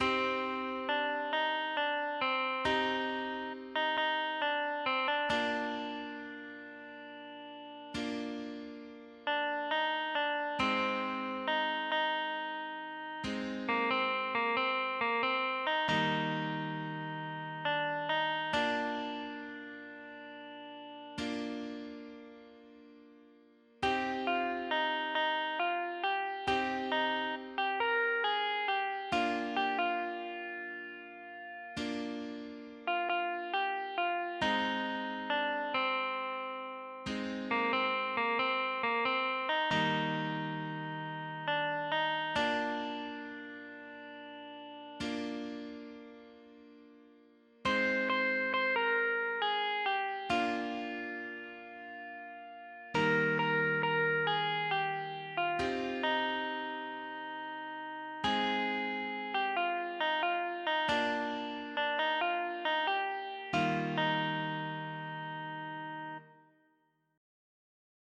(Via Crucis cantado)